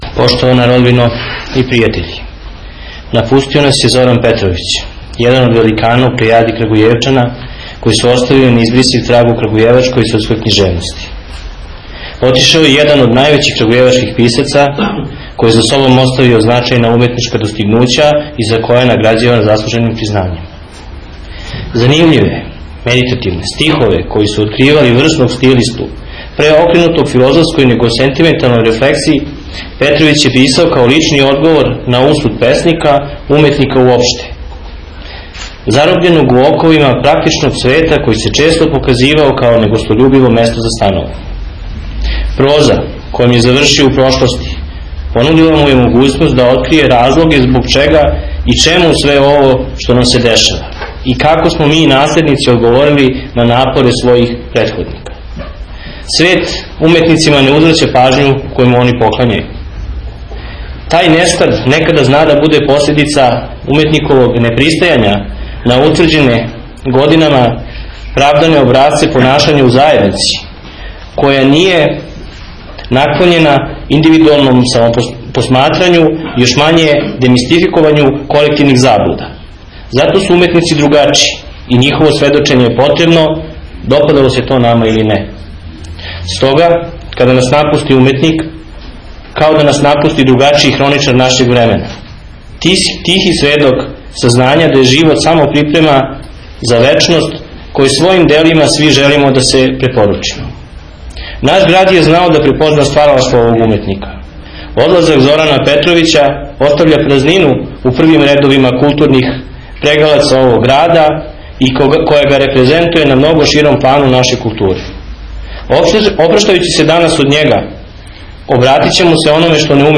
одржана је јуче у крагујевачкој Народној библиотеци.
У име града, говорио је Ивица Момчиловић, заменик градоначелника.
опроштајне беседе